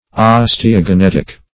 Search Result for " osteogenetic" : The Collaborative International Dictionary of English v.0.48: Osteogenetic \Os`te*o*ge*net"ic\, a. (Physiol.)
osteogenetic.mp3